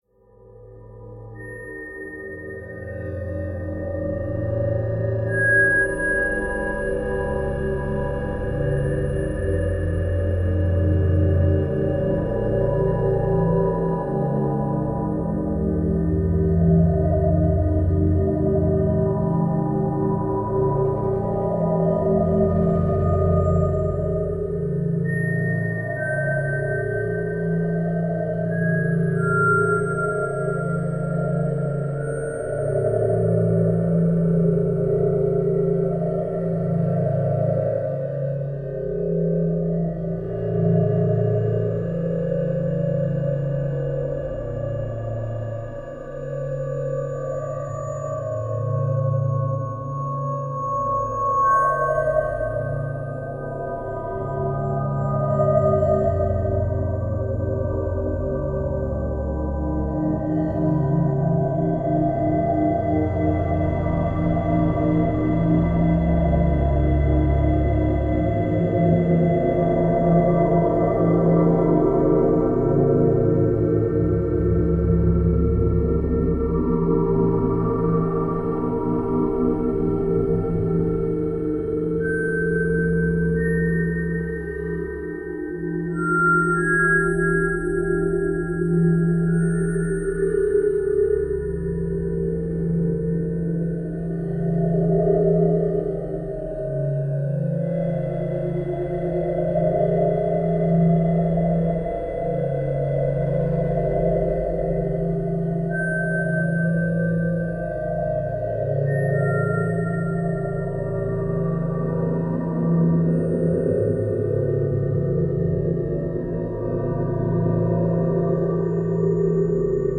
Атмосферные звуки поверхности иной планеты